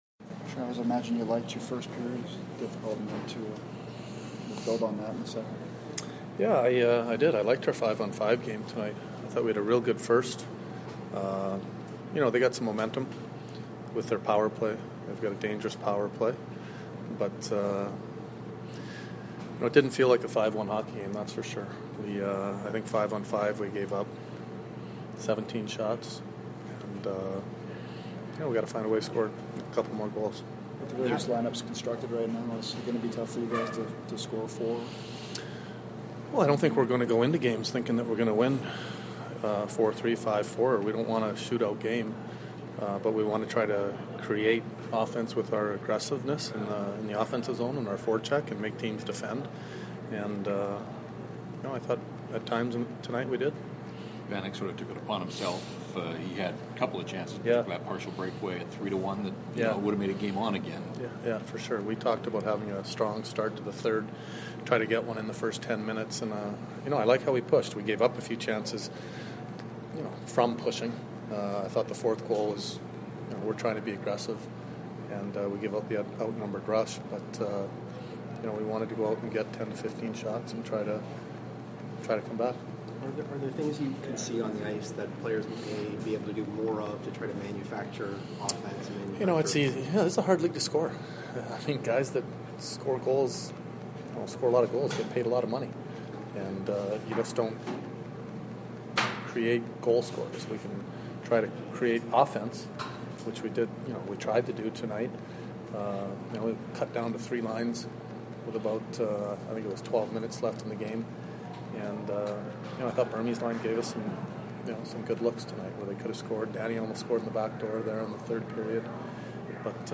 Post-game from the Jets and Canucks dressing rooms as well as from Coach Maurice.